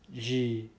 This is a tenative list of words elicited in Shua.
Some recordings are suboptimal and there are errors needing to be corrected. Tone marks are approximate, and the levels indicated here are from highest to lowest s,h,m,l,x. Nasalization is marked with N at the end of the syllable; root-medially, /b/ is pronounced as [β].